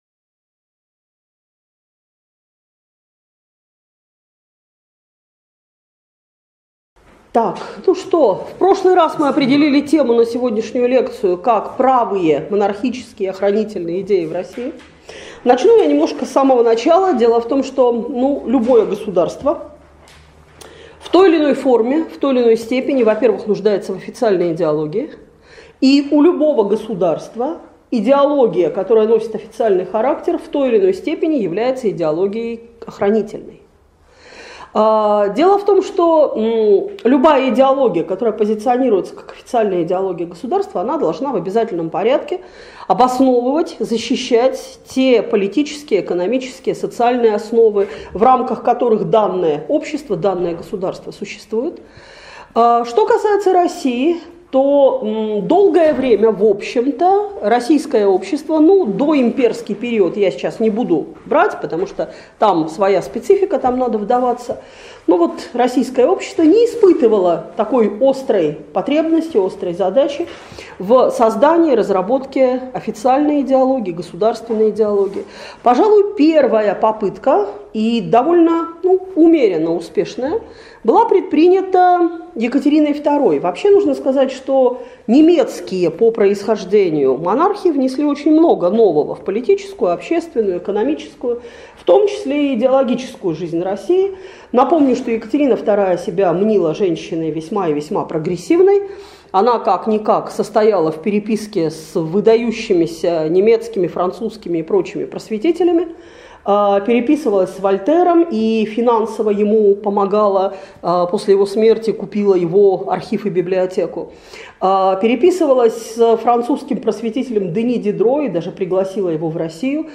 Лекция раскрывает особенности и основные идеи русского консерватизма. Влияние правых консервативно-монархических идеи на формирование общественной мысли накануне революции в России.